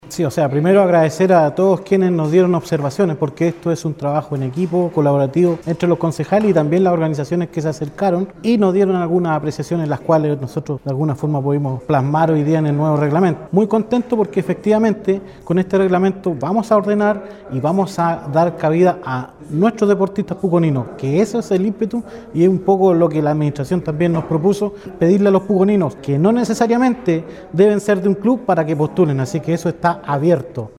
Claudio-Cortez-concejal-y-Pdte-Comision-de-Deportes-valora-el-acuerdo-del-Concejo-Municipal-por-el-Iron-Man.mp3